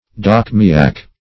dochmiac - definition of dochmiac - synonyms, pronunciation, spelling from Free Dictionary
dochmiac - definition of dochmiac - synonyms, pronunciation, spelling from Free Dictionary Search Result for " dochmiac" : The Collaborative International Dictionary of English v.0.48: Dochmiac \Doch"mi*ac\, a. (Pros.)